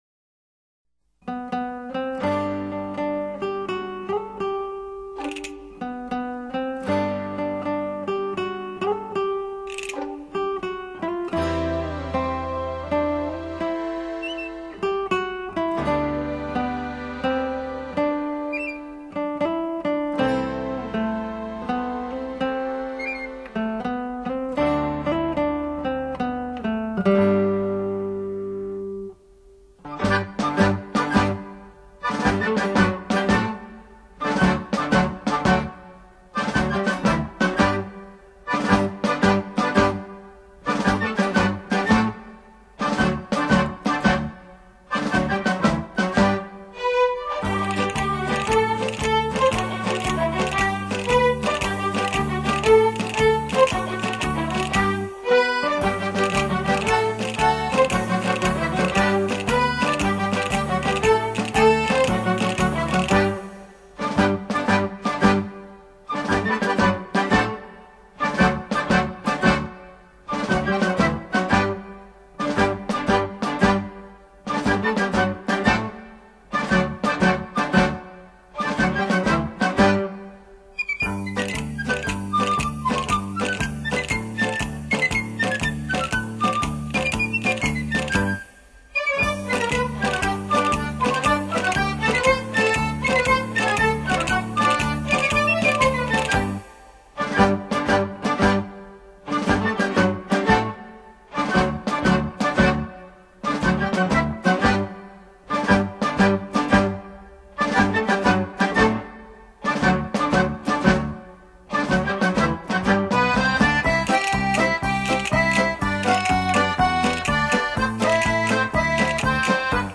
[light]墨西哥民谣：墨西哥帽子舞[/light]
此曲旋律相当特殊，
是由好几段不同的曲调组成，但却不相互冲突，